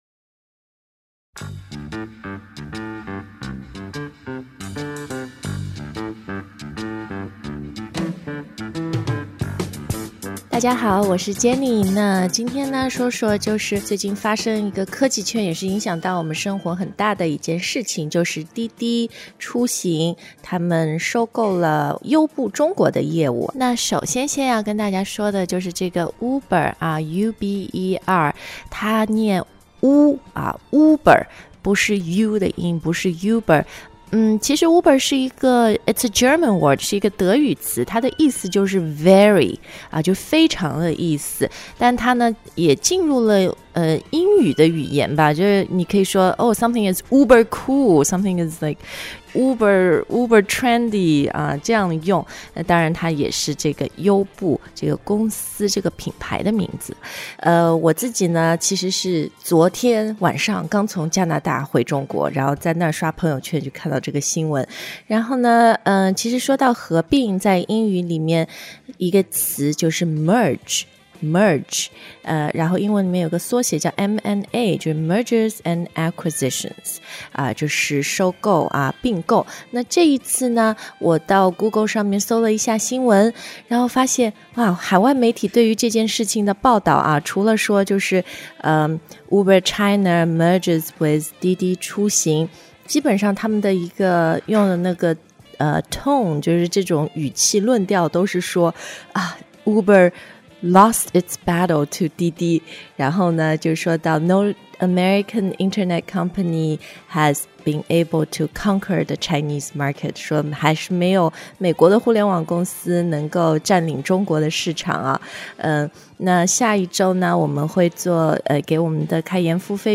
Uber发"wu"的音，不是"you"的音。